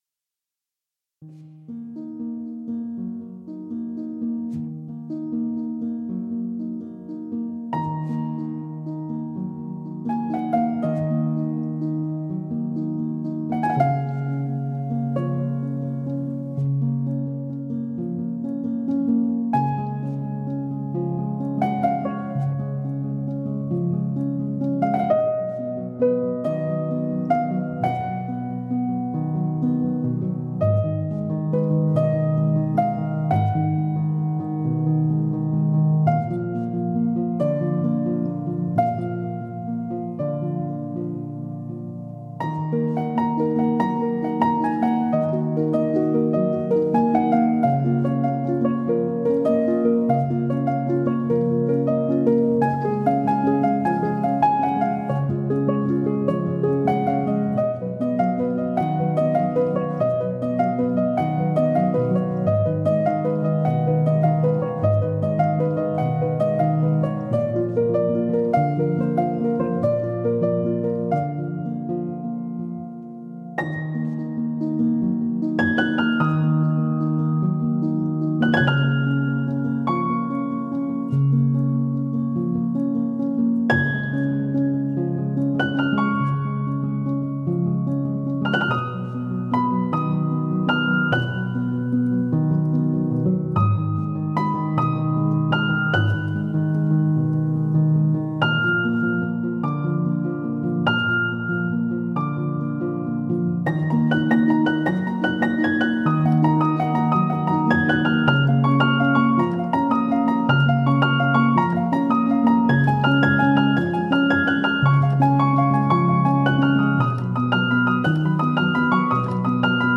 موسیقی بیکلام
Piano Modern classic
پیانو سولو